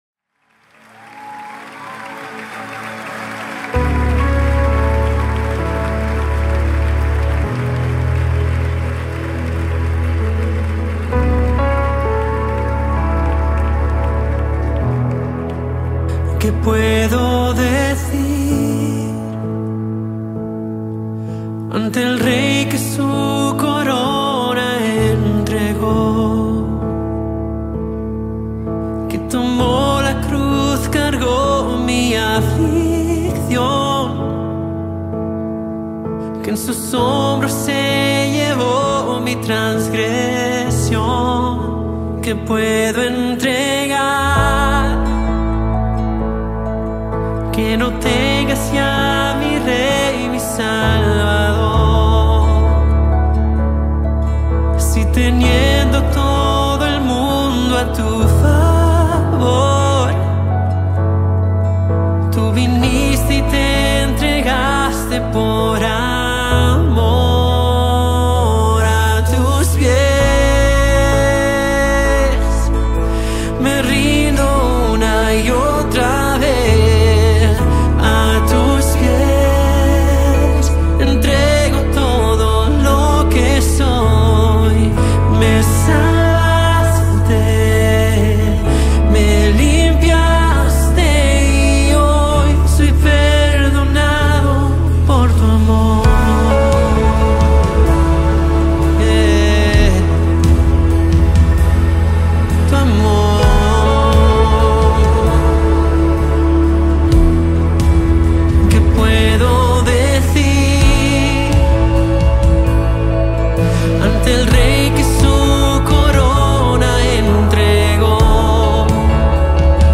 19 просмотров 57 прослушиваний 4 скачивания BPM: 130